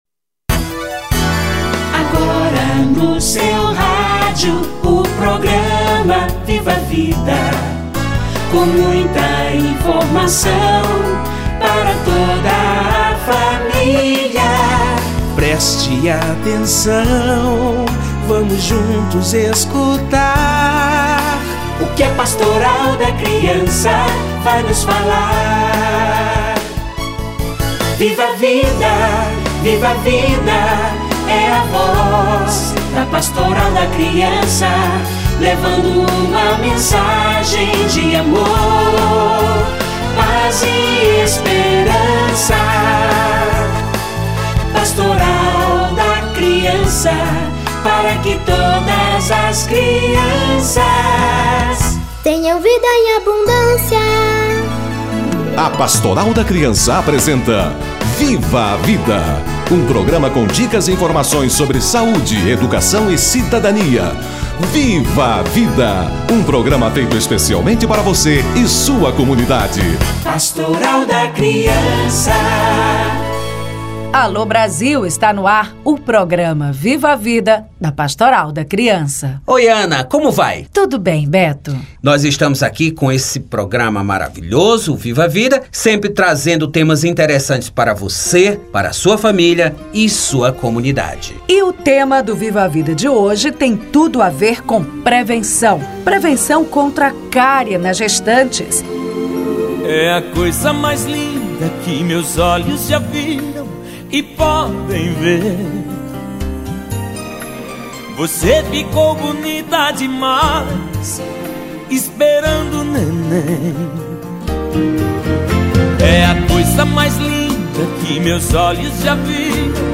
Saúde bucal da gestante - Entrevista